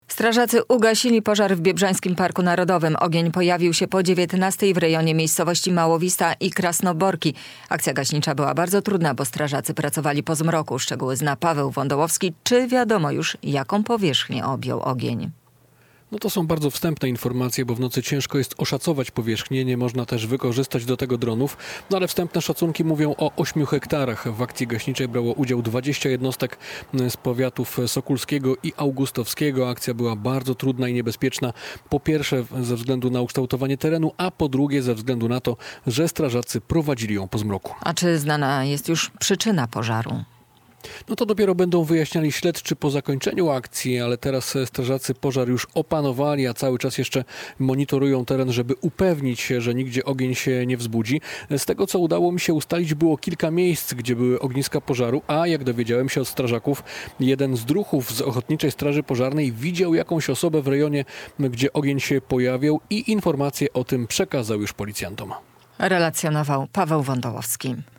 Strażacy ugasili pożar w Biebrzańskim Parku Narodowym - relacja LIVE